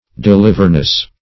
\De*liv"er*ness\